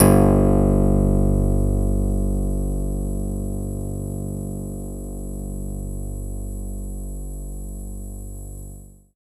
66 BASS   -R.wav